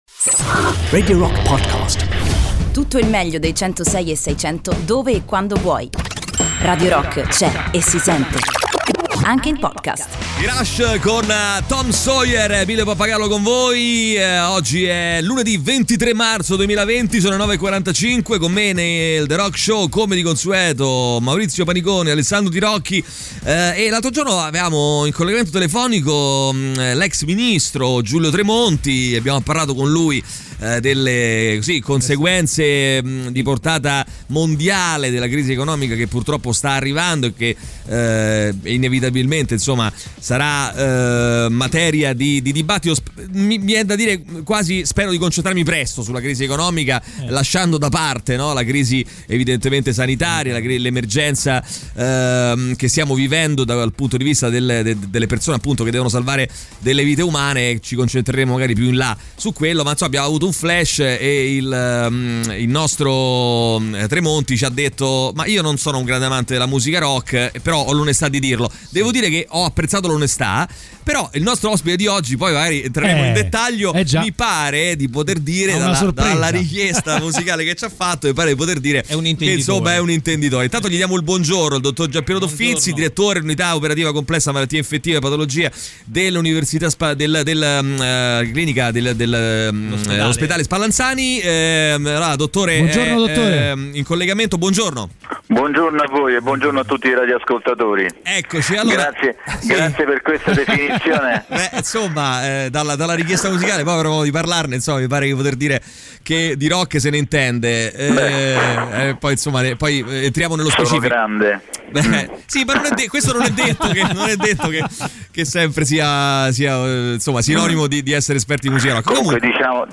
Intervista
Collegamento telefonico